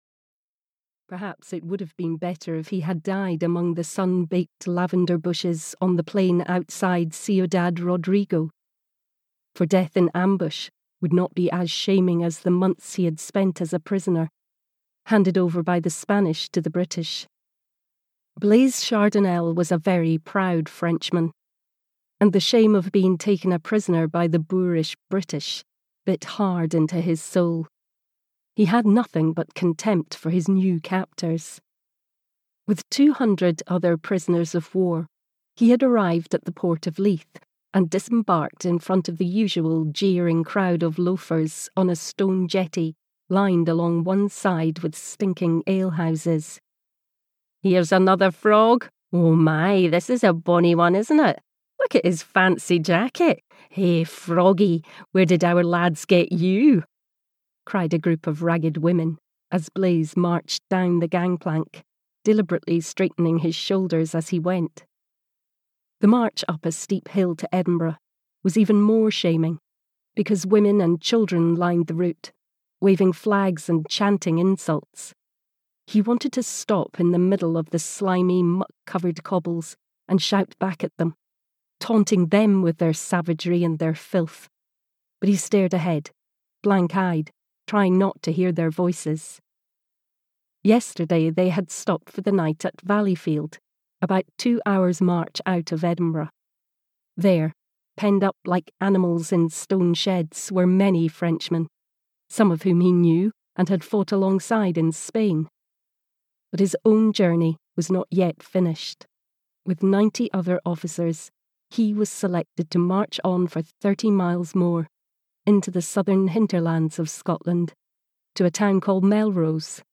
Lark Returning (EN) audiokniha
Ukázka z knihy